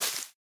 Minecraft Version Minecraft Version latest Latest Release | Latest Snapshot latest / assets / minecraft / sounds / block / azalea_leaves / break6.ogg Compare With Compare With Latest Release | Latest Snapshot